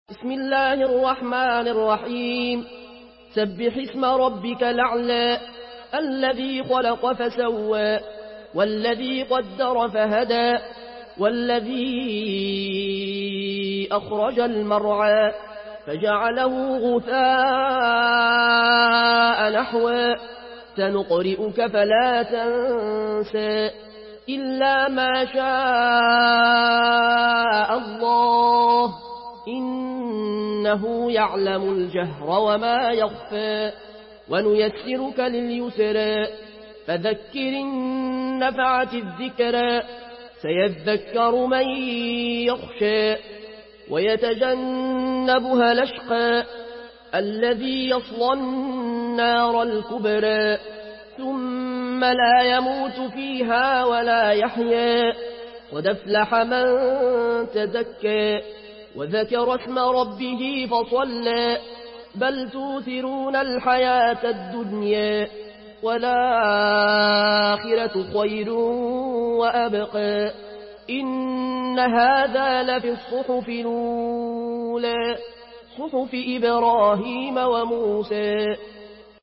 مرتل ورش عن نافع من طريق الأزرق